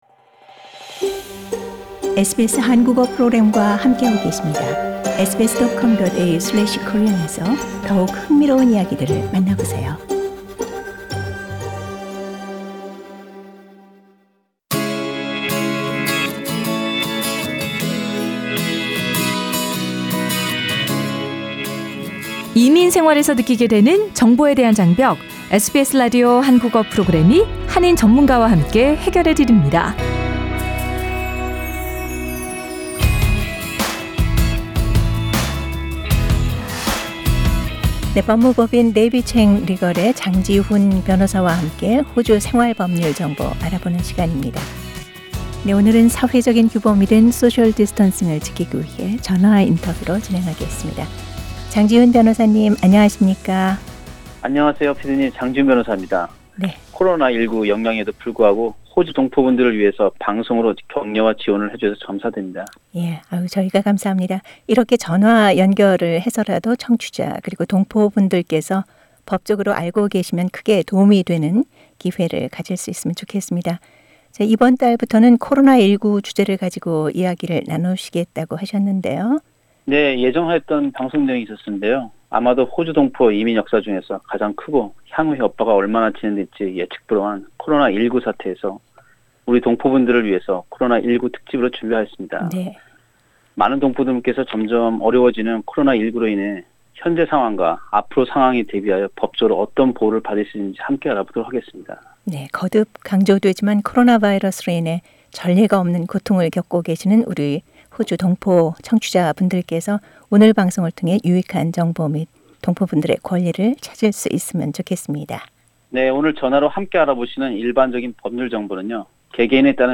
사회적인 규범인 된 사회적 거리두기(social distancing)를 지키기 위해 전화 인터뷰로 진행합니다.